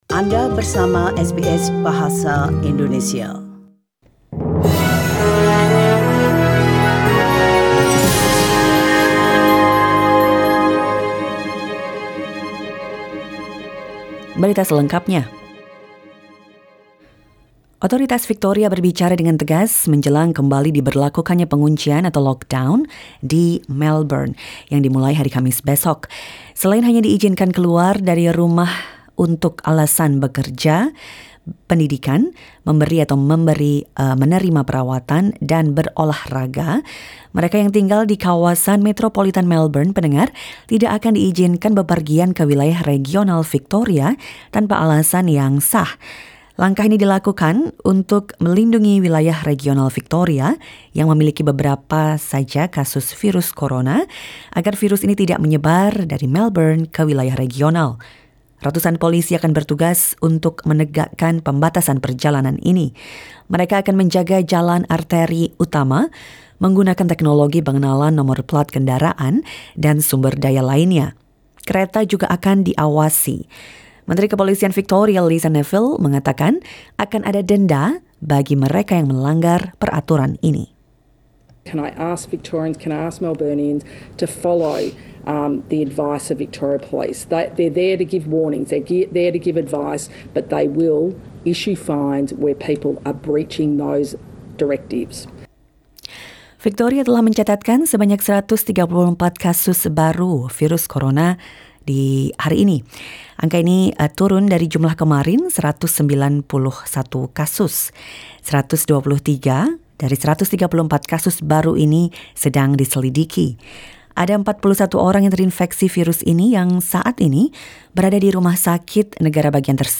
SBS Radio news in Indonesian - 8 July 2020